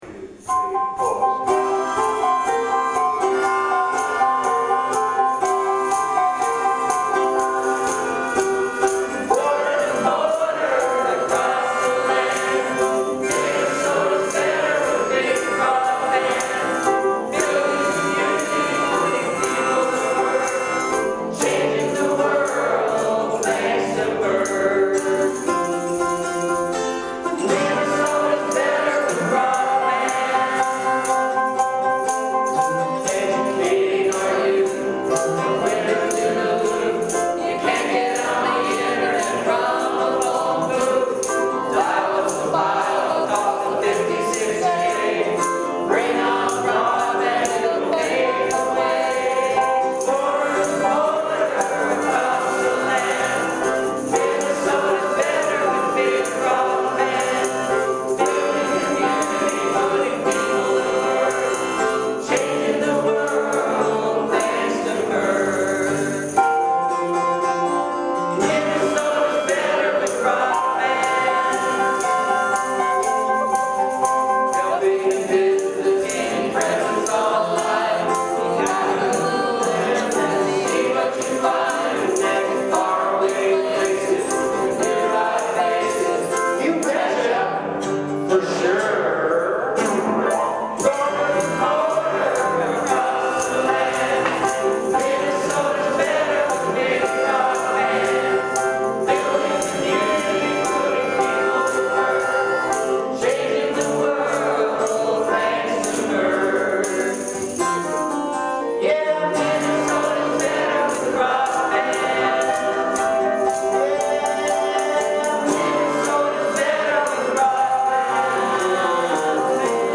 In the evening we had a fun time creating a song to celebrate broadband with the help of Grammy & Emmy nominated